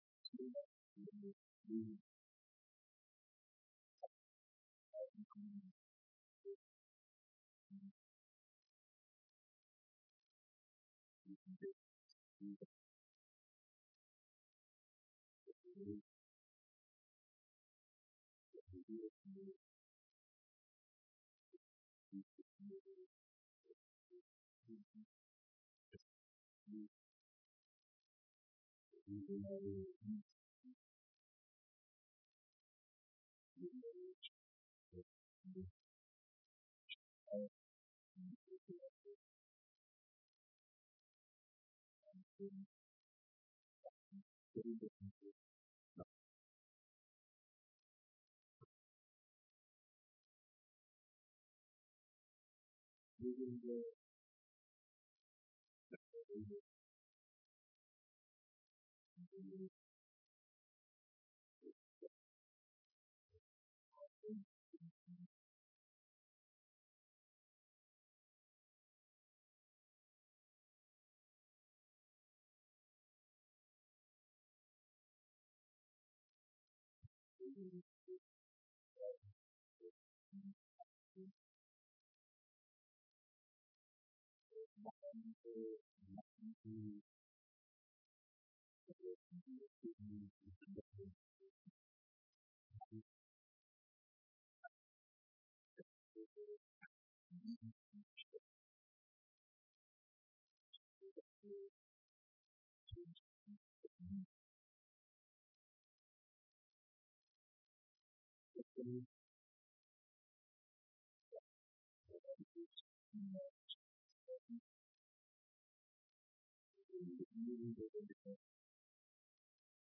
بیانات در دیدار فرماندهان و کارکنان سپاه پاسداران انقلاب اسلامی